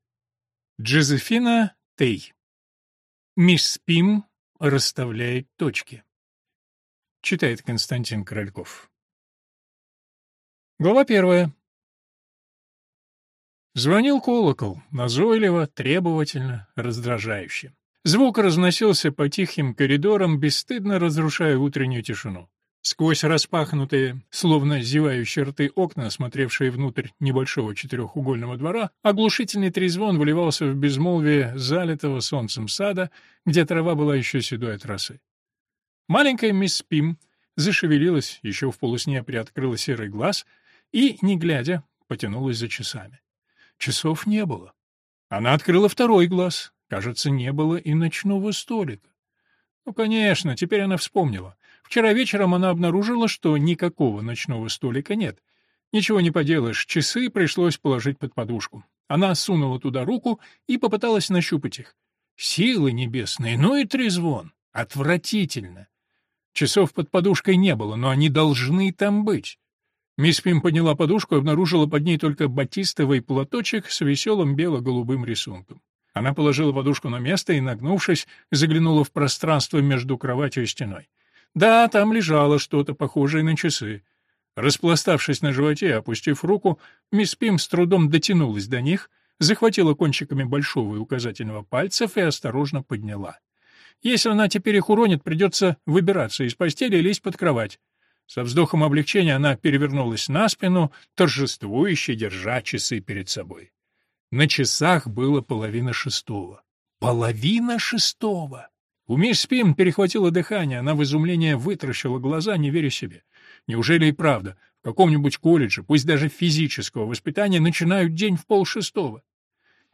Аудиокнига Мисс Пим расставляет точки | Библиотека аудиокниг